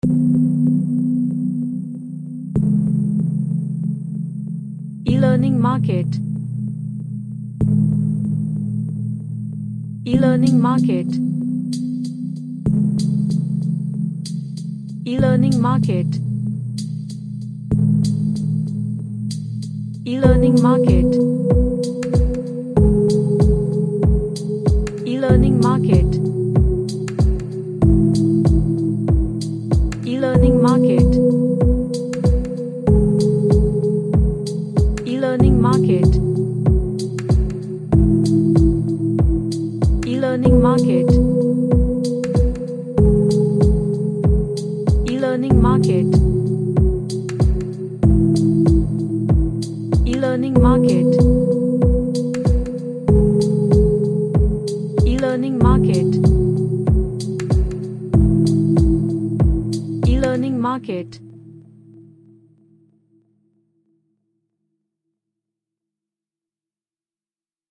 An ambient track with relaxing sine tone.
Relaxation / Meditation